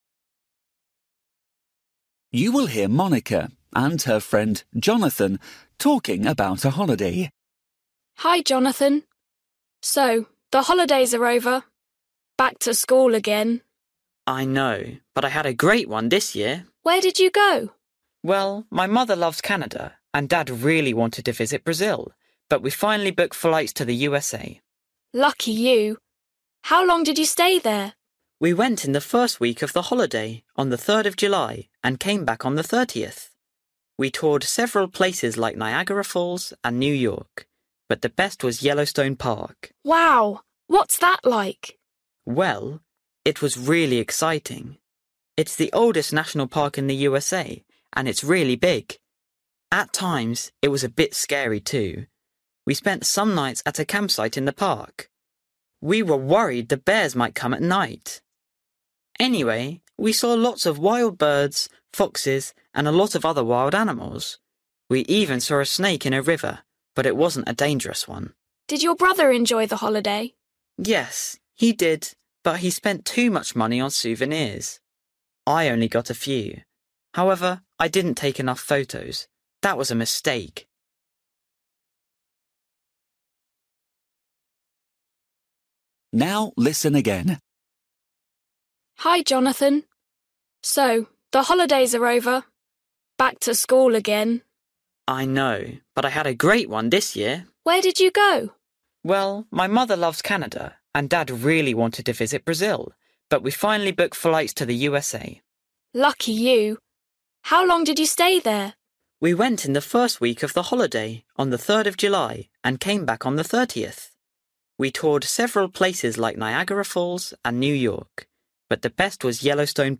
Bài tập trắc nghiệm luyện nghe tiếng Anh trình độ sơ trung cấp – Nghe một cuộc trò chuyện dài phần 35